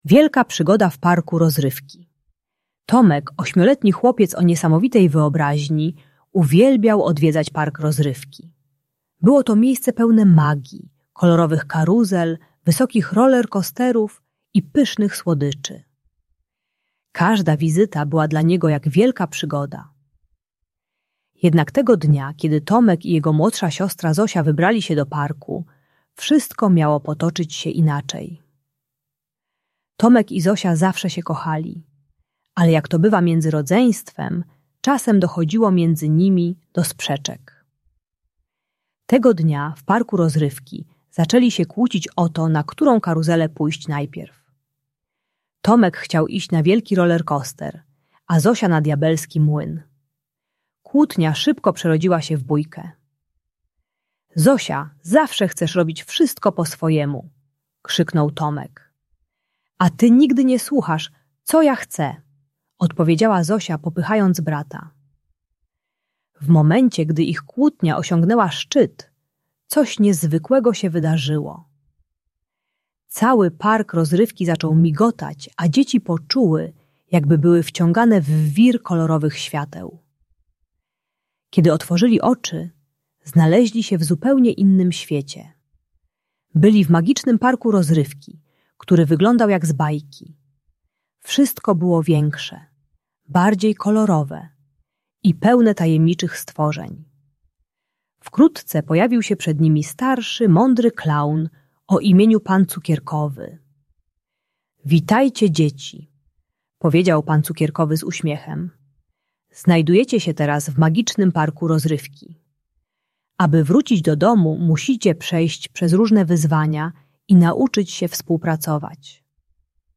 Wielka Przygoda w Parku Rozrywki - Rodzeństwo | Audiobajka